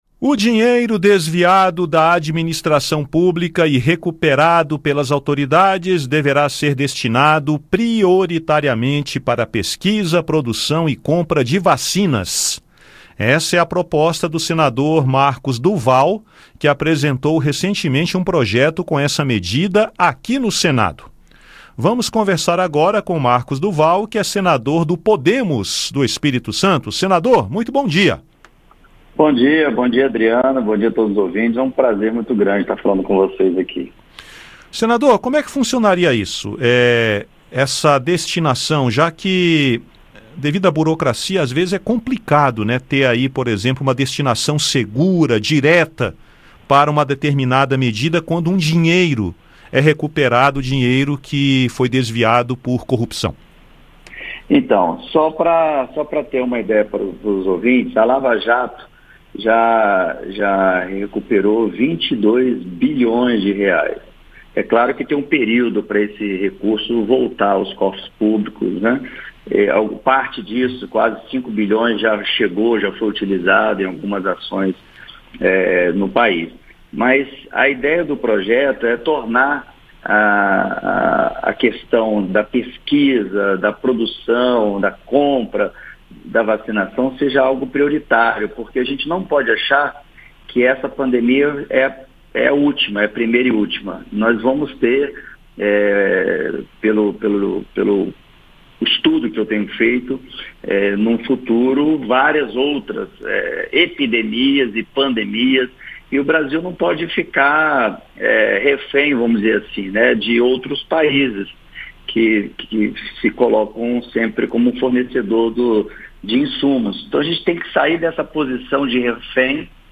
Rádio Senado Entrevista